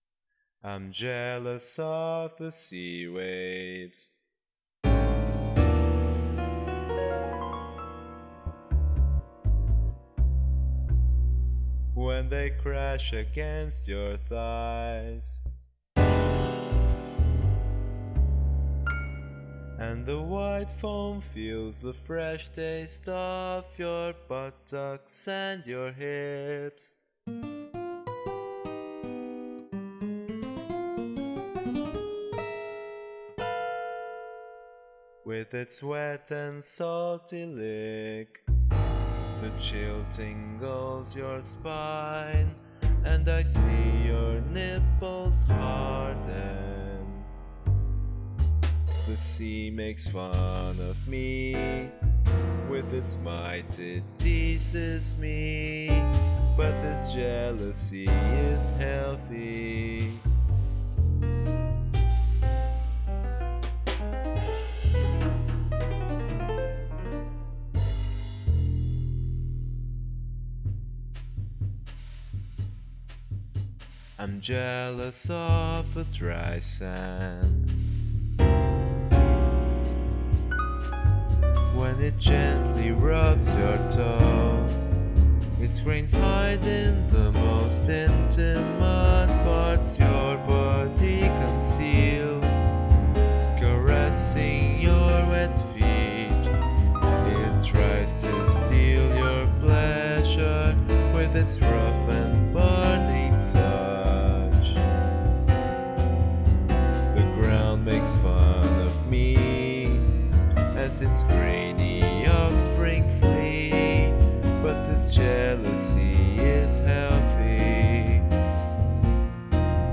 Bossa velha MPBosta dodecafona